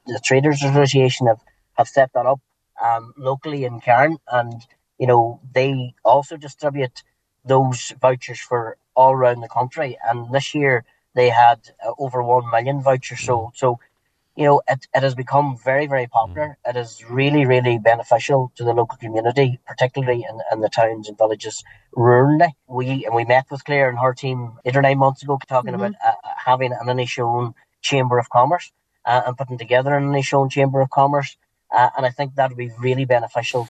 Inishowen councillor Martin McDermott